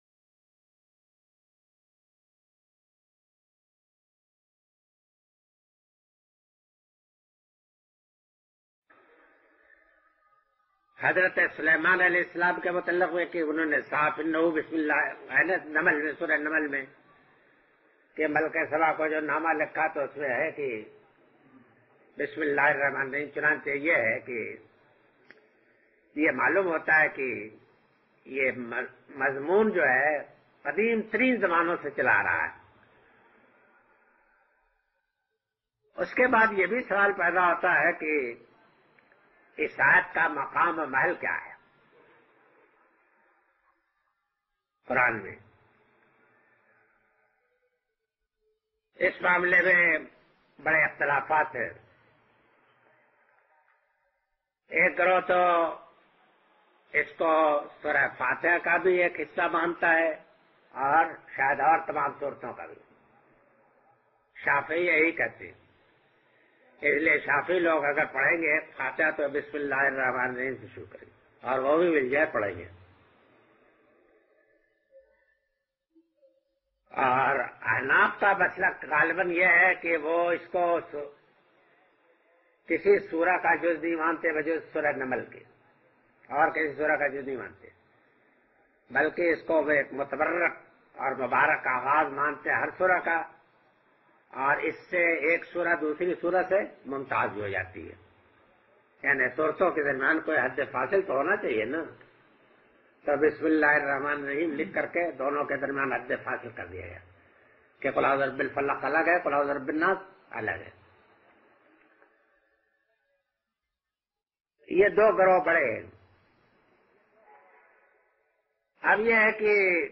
Moulana Amin Ahsan Islahi's Dars-e-Qur'an. Surah Fatihah.